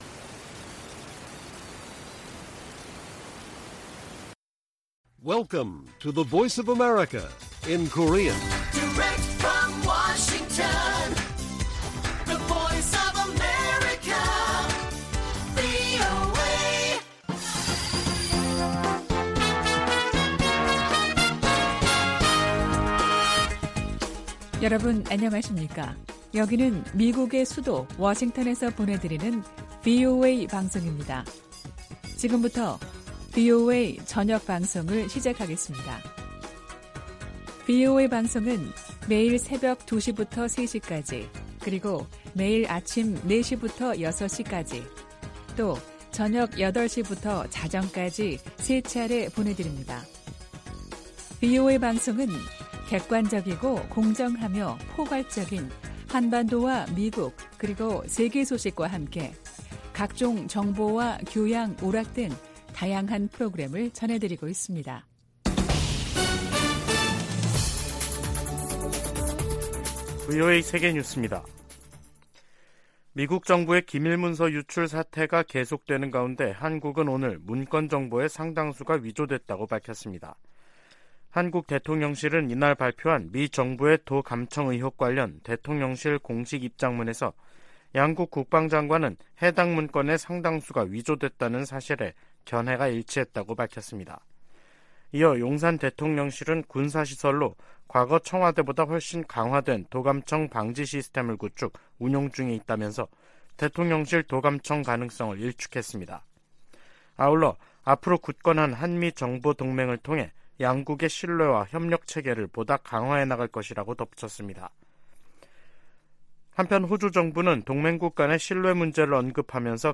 VOA 한국어 간판 뉴스 프로그램 '뉴스 투데이', 2023년 4월 11일 1부 방송입니다. 미국 정부는 정보기관의 한국 국가안보실 도·감청 사안을 심각하게 여기며 정부 차원의 조사가 이뤄지고 있다고 밝혔습니다. 미국 전문가들은 이번 도청 의혹이 두 나라 간 신뢰에 문제가 발생했다고 평가하면서도, 다가오는 미한 정상회담에 큰 영향은 없을 것으로 내다봤습니다. 김정은 북한 국무위원장이 인민군 지휘관들에게 핵 무력을 공세적이고 효과적으로 운용하라고 강조했습니다.